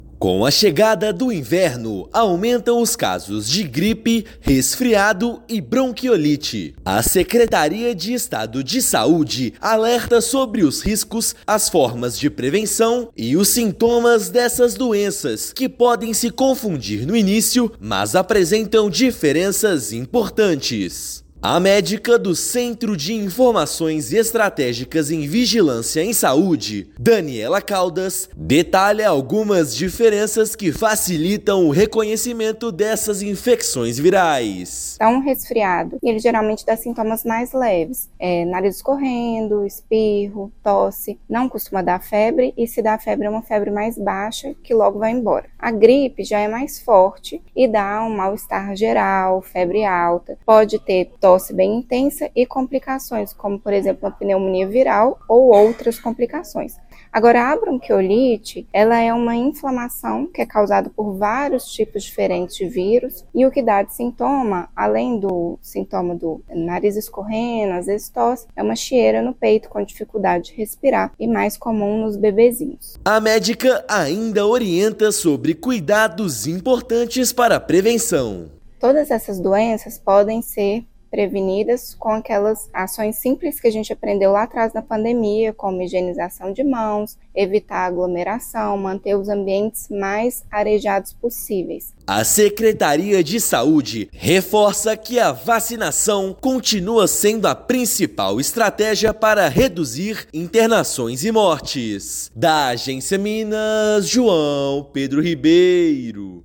Secretaria de Saúde orienta sobre prevenção e sintomas das infecções mais comuns nesta época do ano. Ouça matéria de rádio.